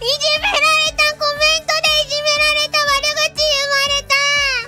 Worms speechbanks
Traitor.wav